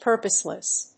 音節pur･pose･less～･ly～･ness発音記号・読み方pə́ːrpəsləs
• / ˈpɝpʌslʌs(米国英語)
• / ˈpɜ:pʌslʌs(英国英語)